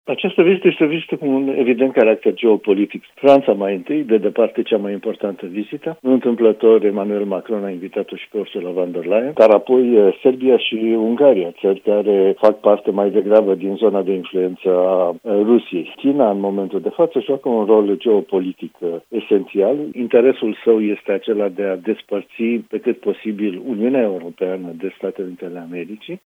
analist politic